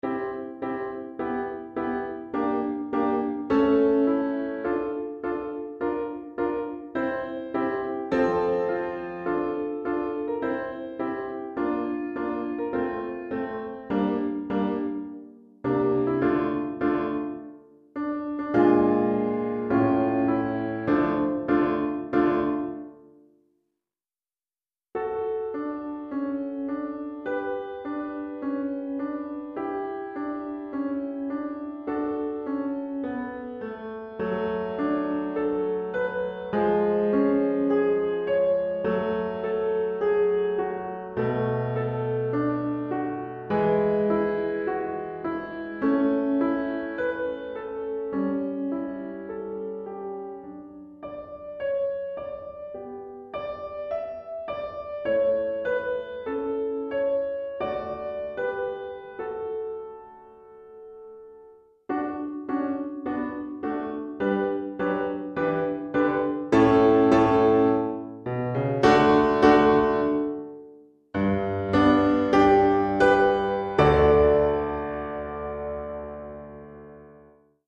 Répertoire pour Piano